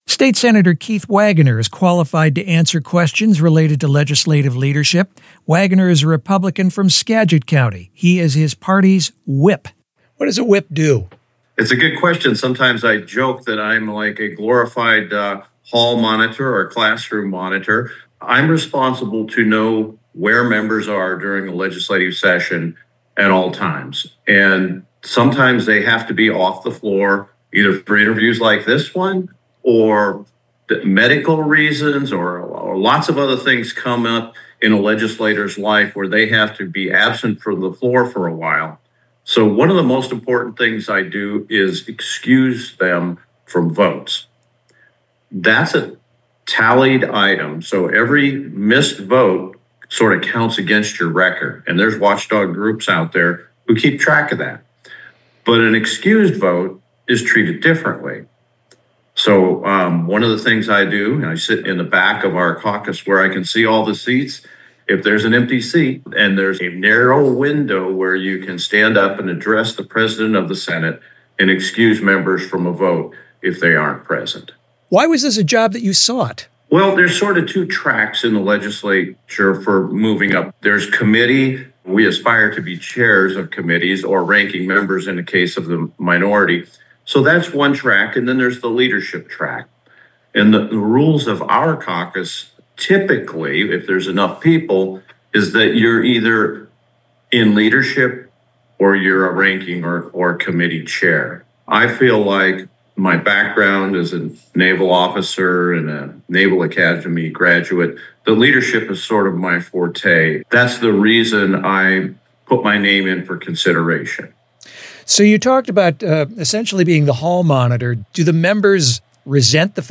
Sen. Keith Wagoner answer questions related to legislative leadership, including – what’s a whip? Wagoner represents Skagit and Snohomish counties and serves as the Senate Republican Caucus Whip.